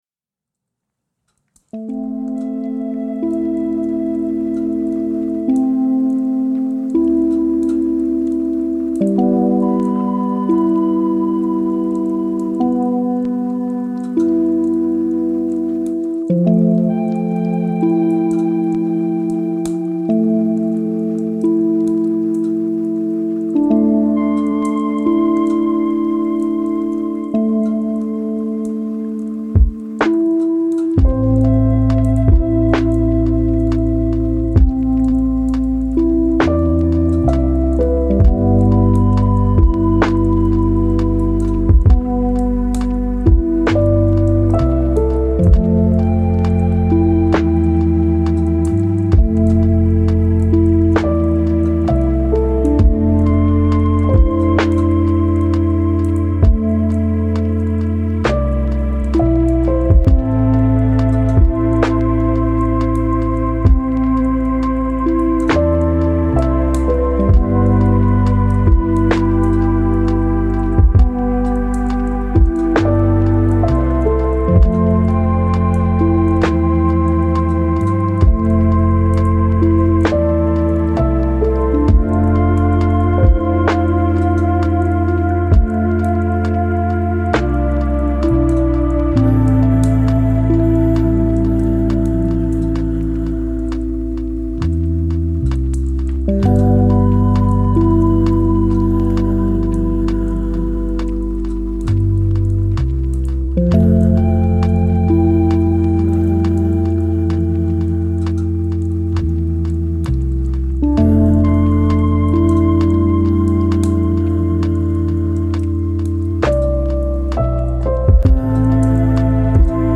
Méditation Pyramide : 33+9 Hz